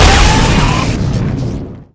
TazerAlt.ogg